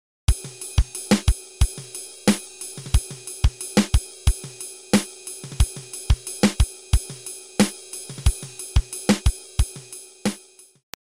This turned up on an old groove tape the other day. It’s a simple one-bar thing in straight time with a basic eighth-note ride pattern. The ghost notes give it a nice hypnotic feel, setting off the interplay between kick and snare accents.
The snare on the a of 4 is actually played as two 32nd notes — a ruff into the next downbeat on the kick. 1e+a2e+a3e+a4e+a RC o o o o o o o o SD o o O o O o KD o o o o